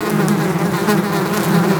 MULTI INS04L.wav